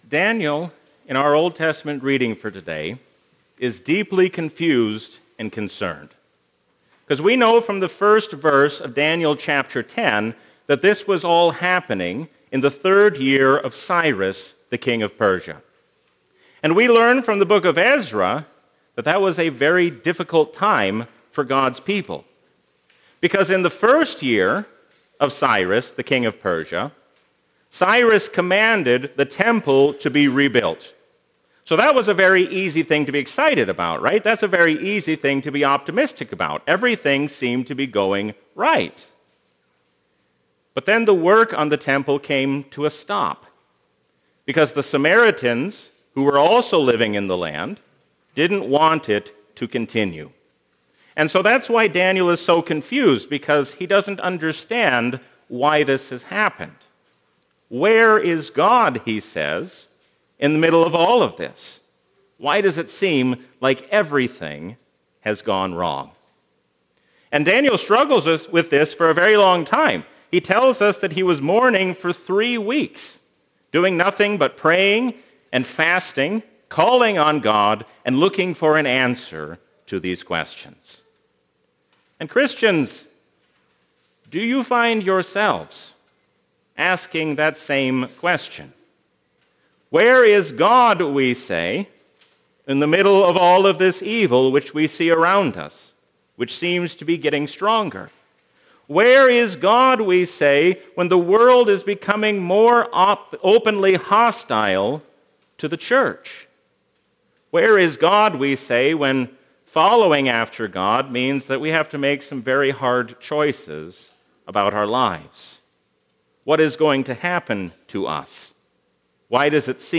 A sermon from the season "Trinity 2021." Stand firm against worldly powers, because Jesus reigns as King forever.